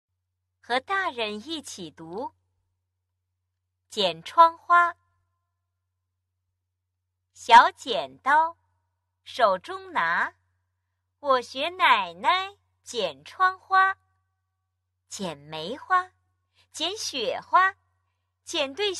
统编版一年级上册 语文园地三 剪窗花 朗读 音视频素材